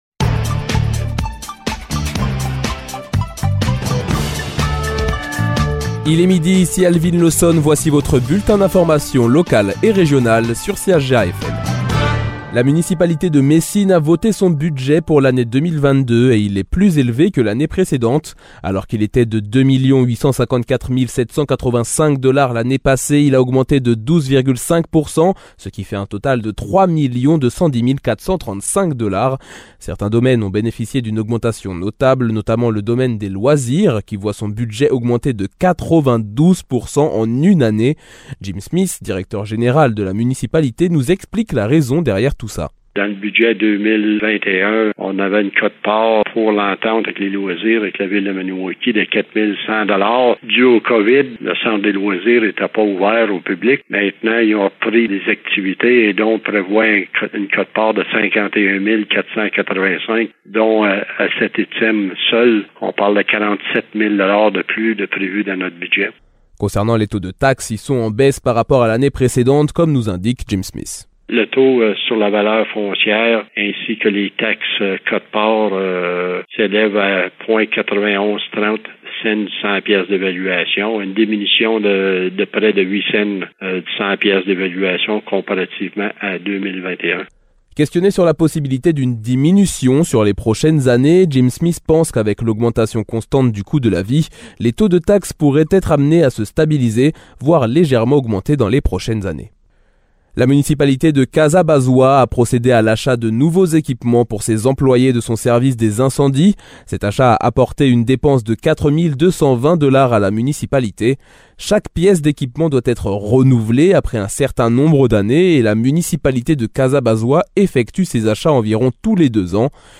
Nouvelles locales - 14 février 2022 - 12 h